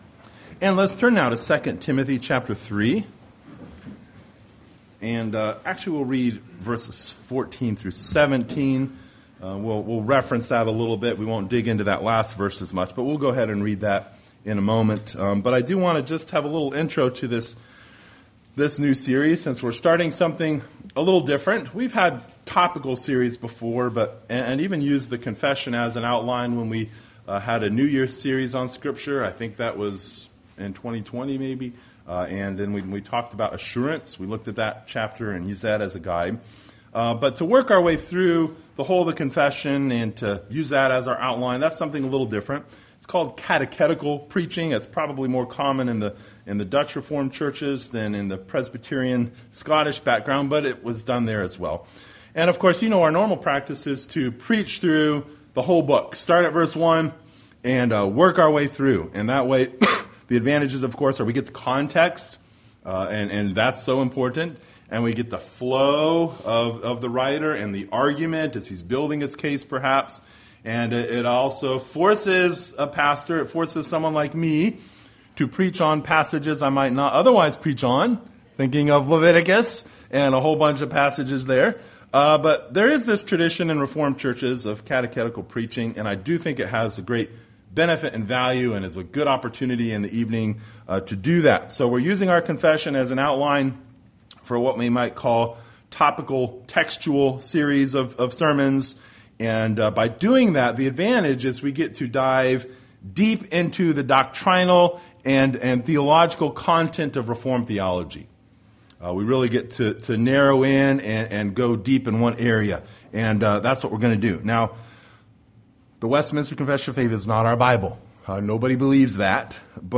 Series: The Westminster Confession of Faith Passage: II Timothy 3:14-16 Service Type: Sunday Evening